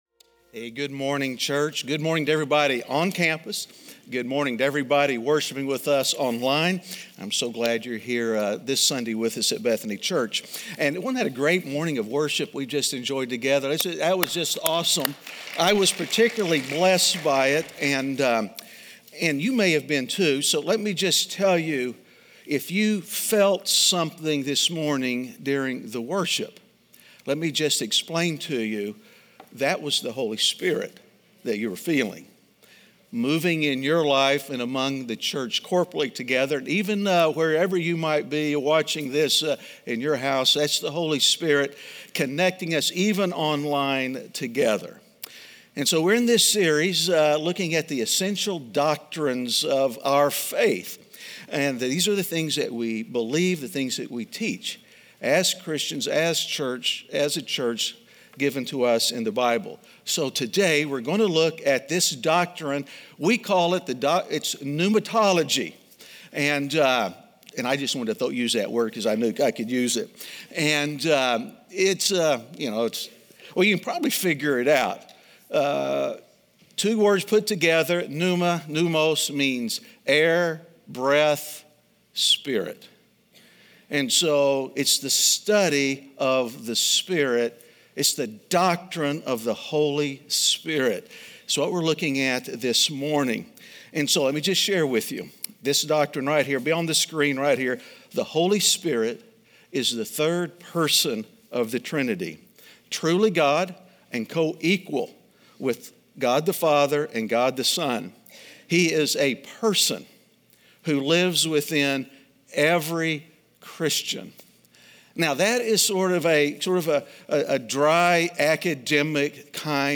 Is There A Doctrine In The House? (Week 6) - Sermon.mp3